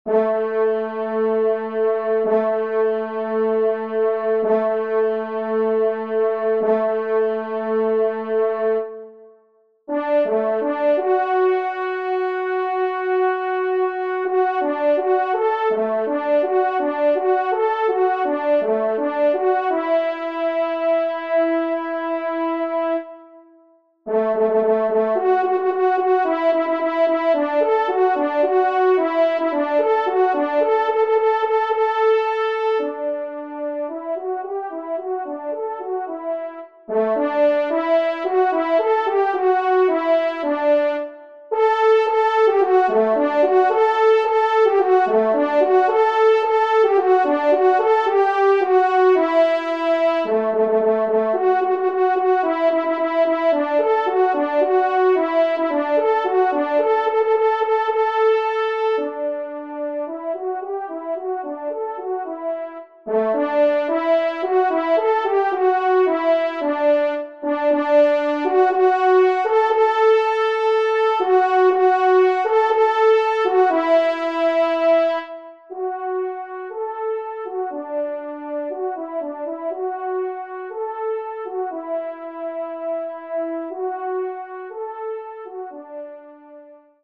1e Trompe